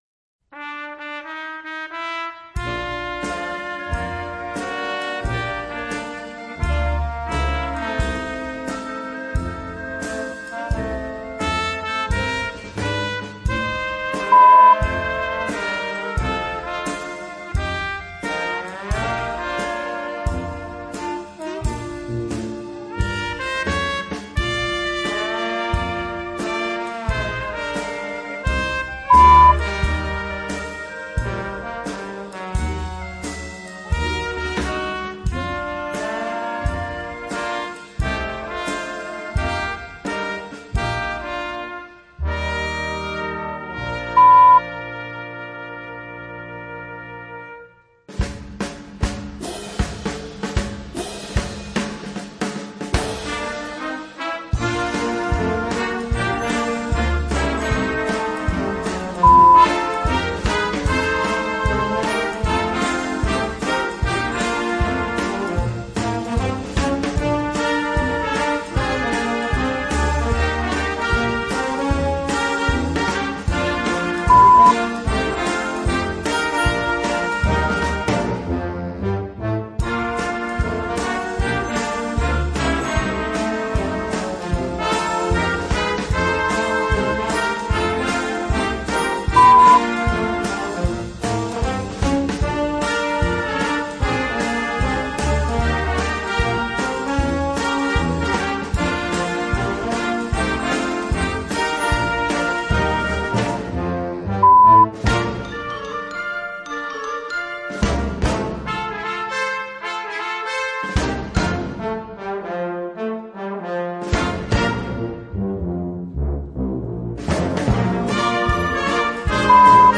Gattung: Jugendblasorchester
Besetzung: Blasorchester